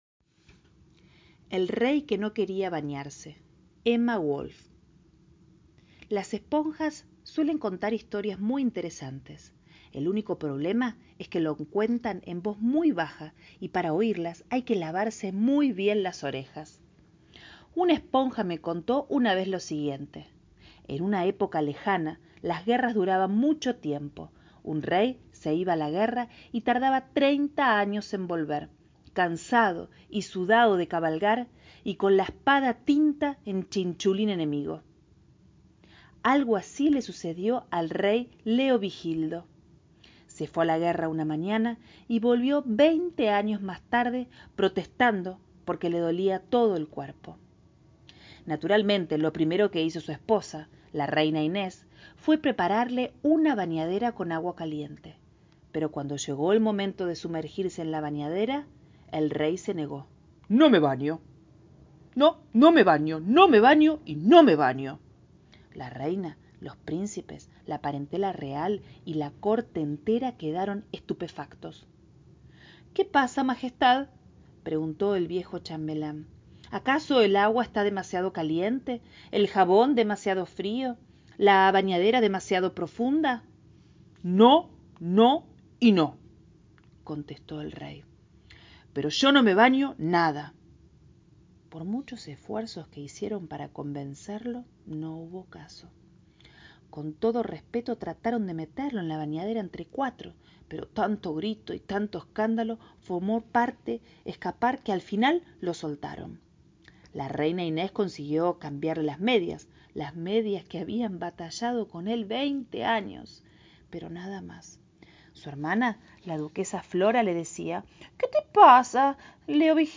Nuevamente literatura para la infancia y no solo!